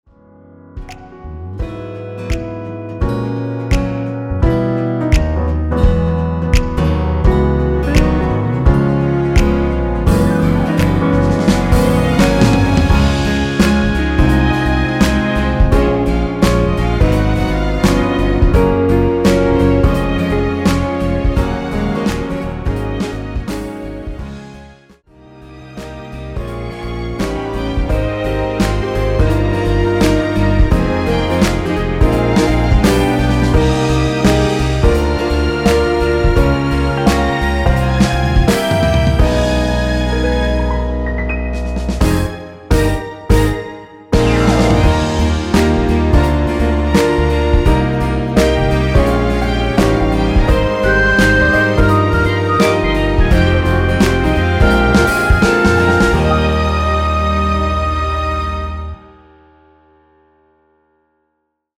엔딩이 페이드 아웃이라 노래 하기 좋게 엔딩을 만들어 놓았습니다.(미리듣기 참조)
원키에서(-2)내린(짧은편곡)MR입니다.
앞부분30초, 뒷부분30초씩 편집해서 올려 드리고 있습니다.
중간에 음이 끈어지고 다시 나오는 이유는